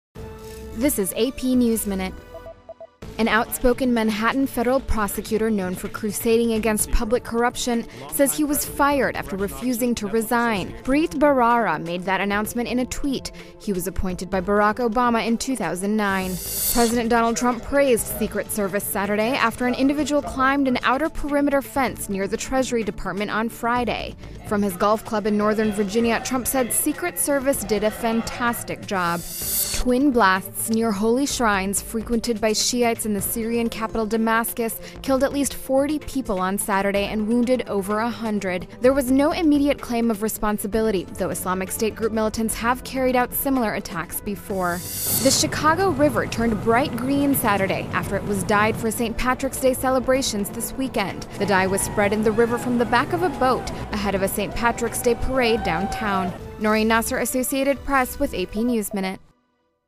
News
美语听力练习素材:芝加哥河水变绿庆祝圣帕特里克节